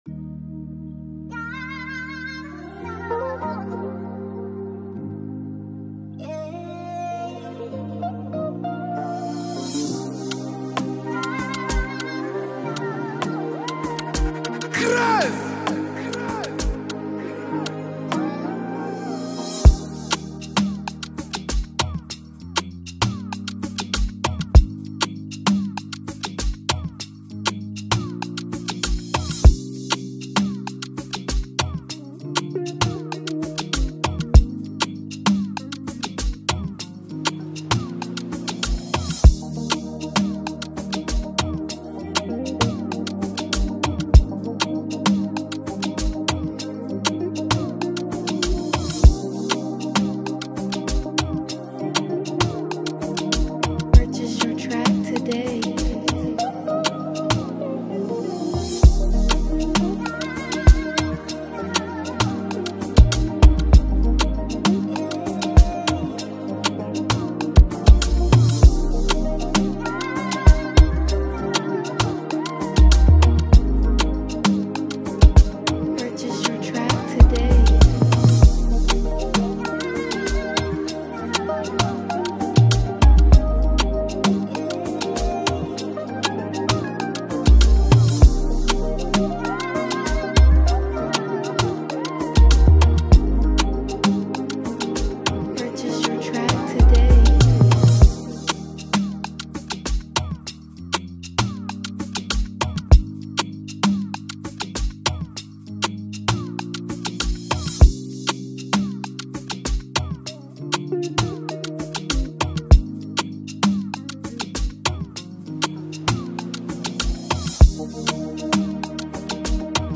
mesmerizing free emotional afrobeat instrumental
and it has a great melody to create that hit single.